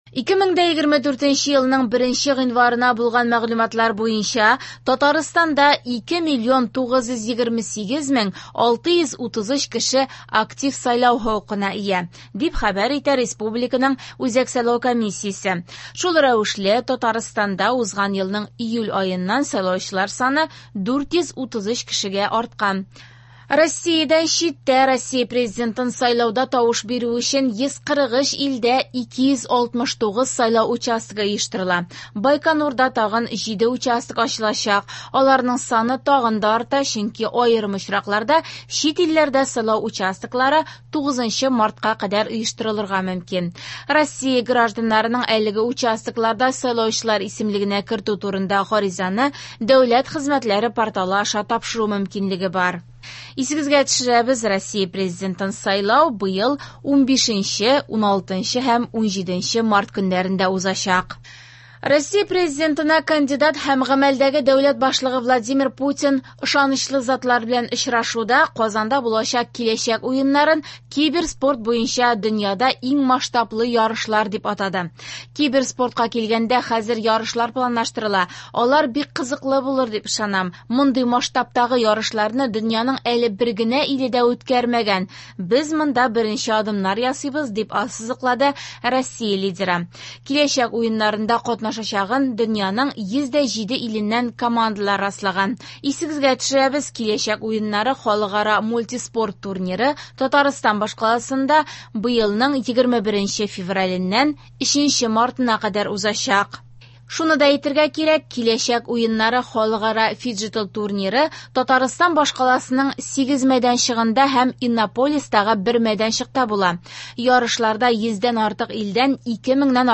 Яңалыклар (5.02.24)